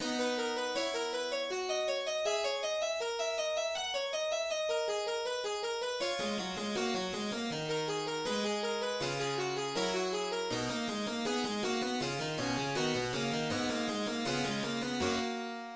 Si majeur
Métrique 4/4
Prélude